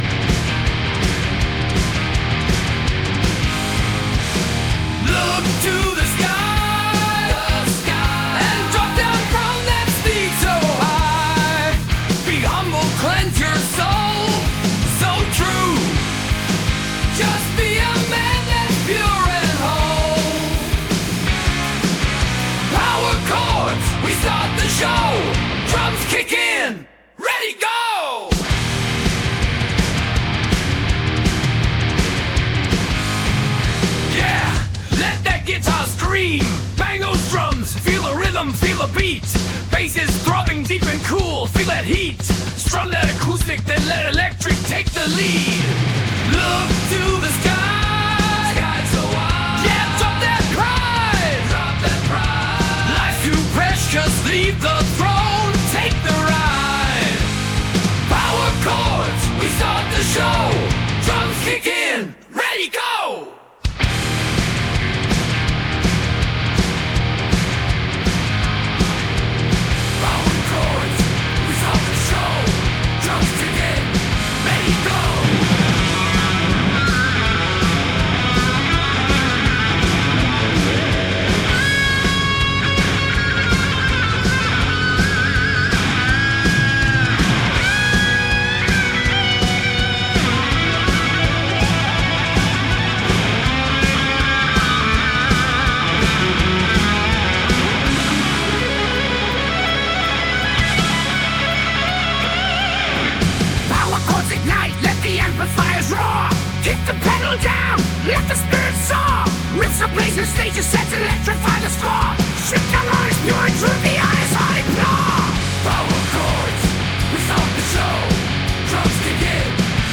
Рок-группа
Получилось очень мощно.